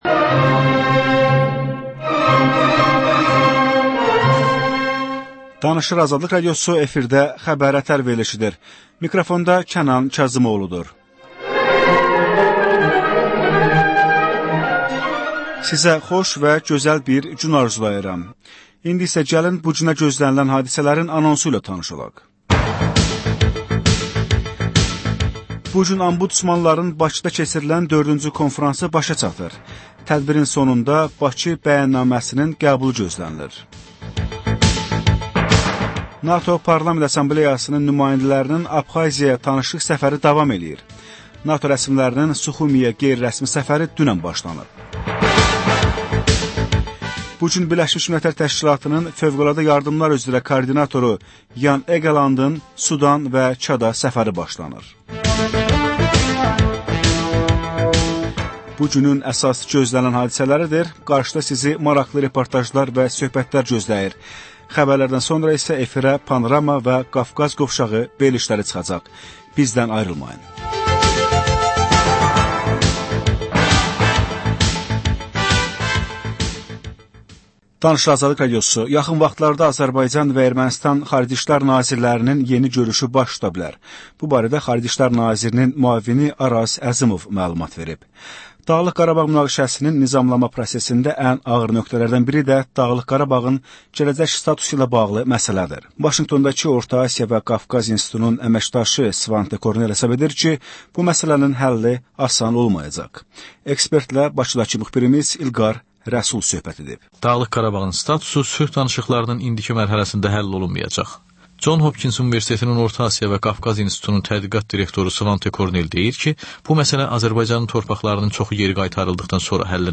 Hadisələr, reportajlar. Panorama: Jurnalistlərlə həftənin xəbər adamı hadisələri müzakirə edir.